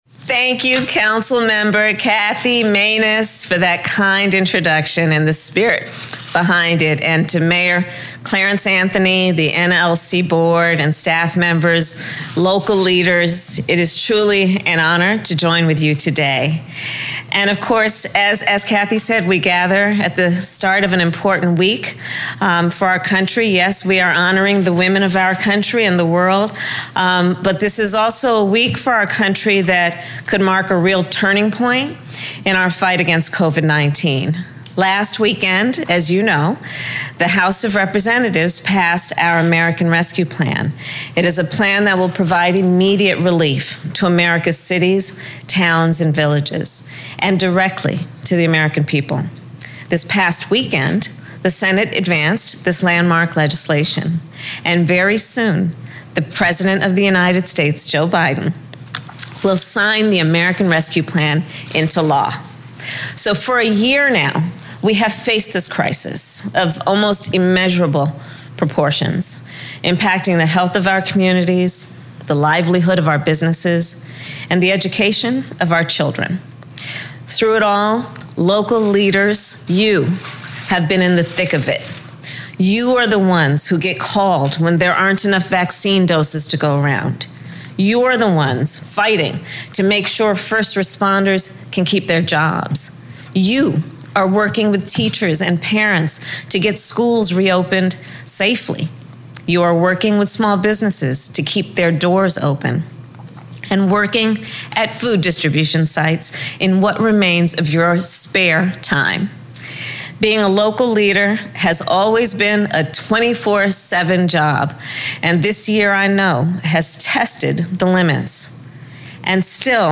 Kamala Harris - Address to the National League of Cities Conference (text-audio-video)